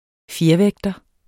Udtale [ ˈfjeɐ̯ˌvεgdʌ ]